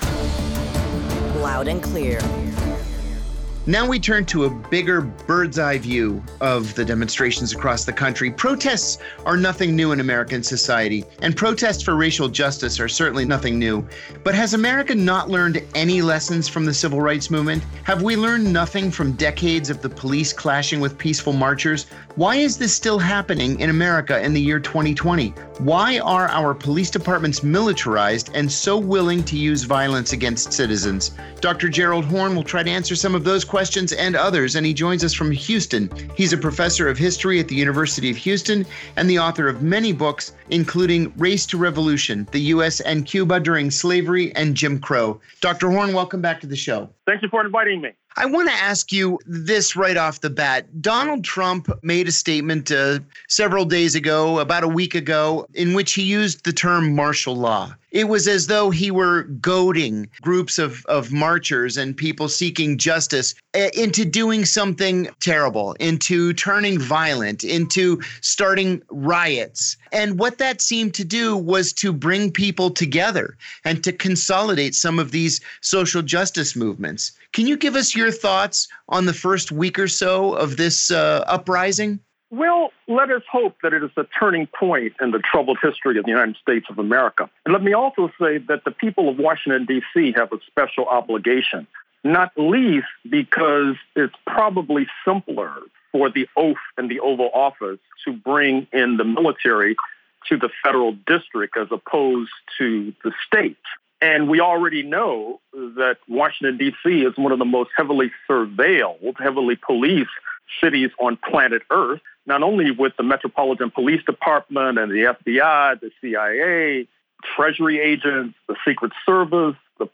Gerald Horne, radical commentator
via Radio Sputnik